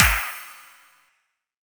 clapOffbeat.ogg